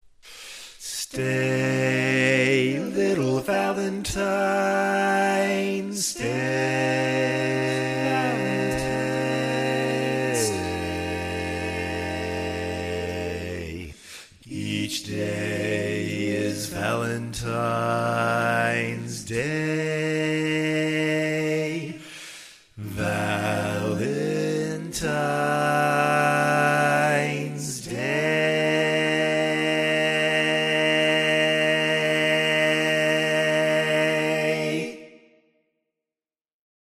Key written in: B Minor
Type: Barbershop